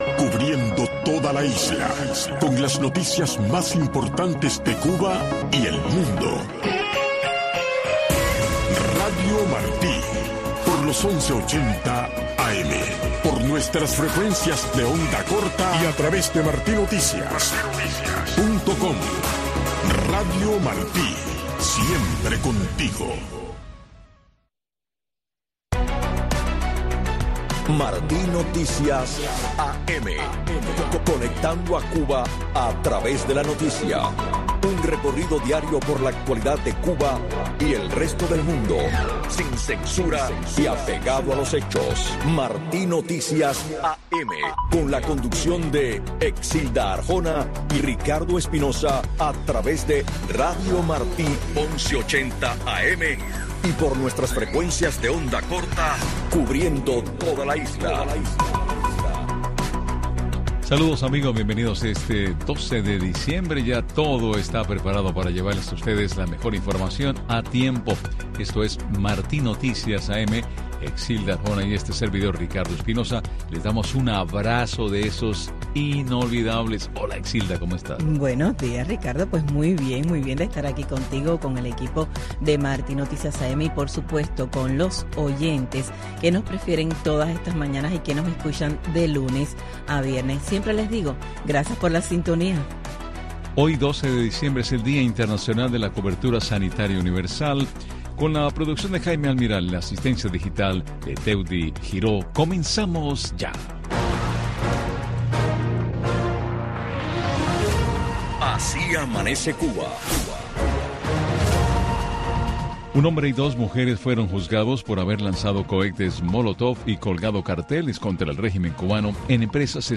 Revista informativa con los últimos acontecimientos ocurridos en Cuba y el mundo. Con entrevistas y temas de actualidad relacionados a la política, la economía y de interés general.